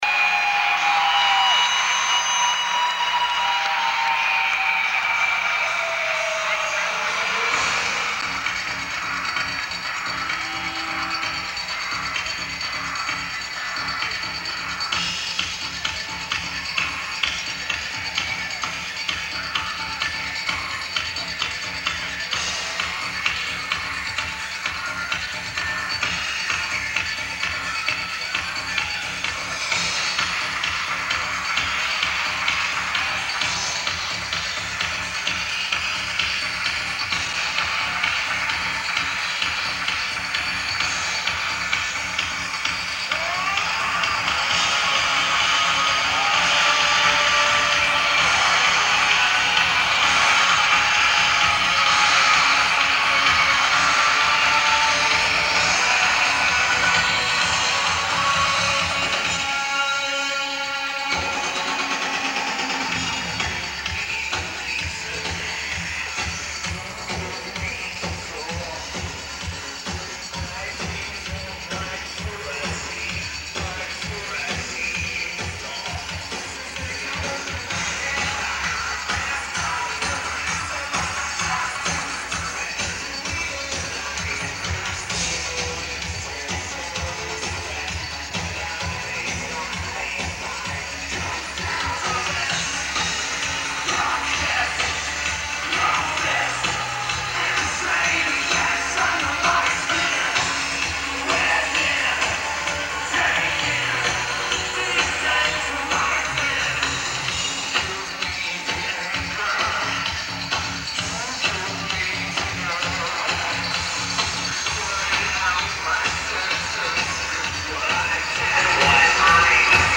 Lineage: Audio - AUD, Unknown Audience Recording